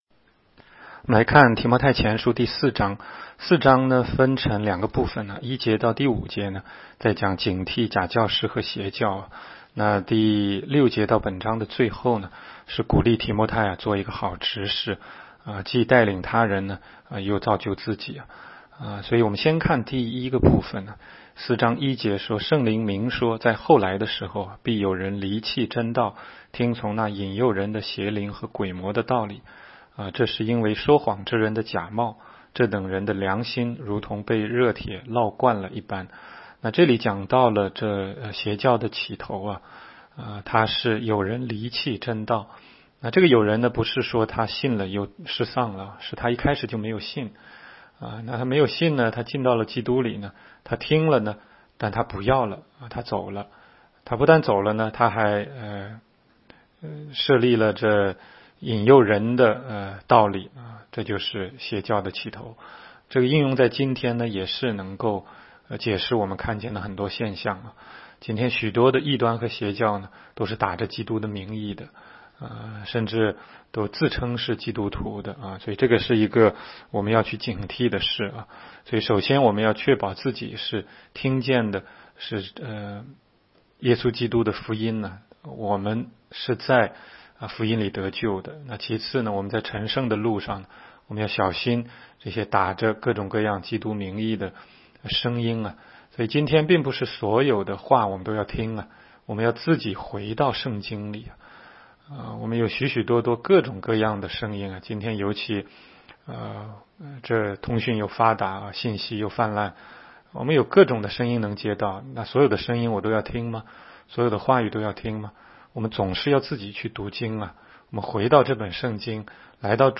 16街讲道录音 - 每日读经-《提摩太前书》4章
每日读经